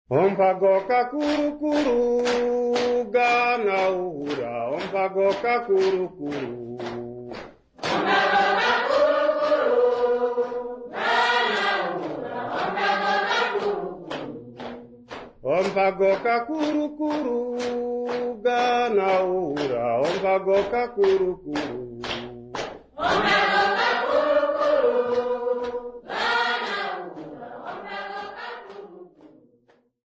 Bunya music workshop participants
Folk music
Sacred music
Africa Namibia Rundu sx
field recordings
Kwangali religious song accompanied by drums and clapping